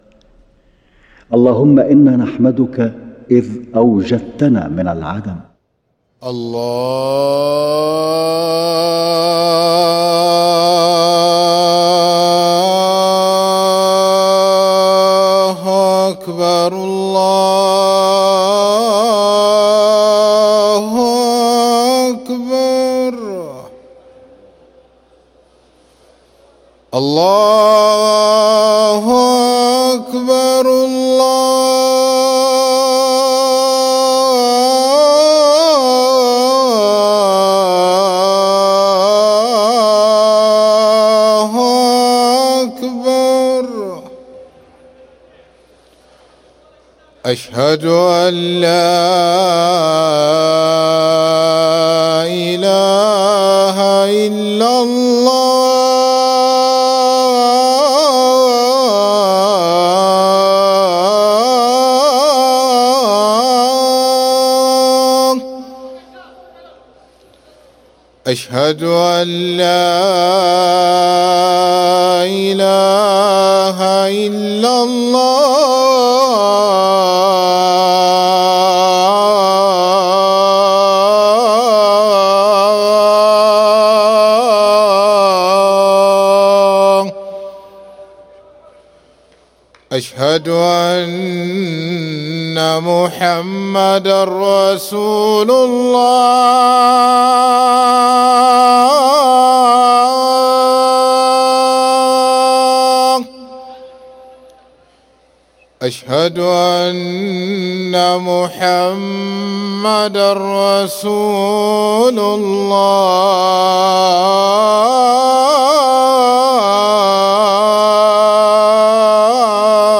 أذان الفجر للمؤذن سعيد فلاته الخميس 11 ذو الحجة 1444هـ > ١٤٤٤ 🕋 > ركن الأذان 🕋 > المزيد - تلاوات الحرمين